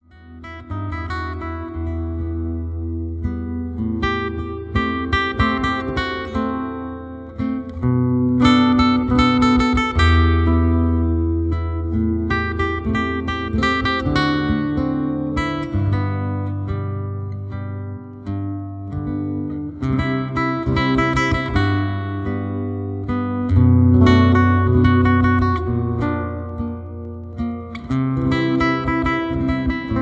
Solo Guitar Standards
Soothing and Relaxing Guitar Music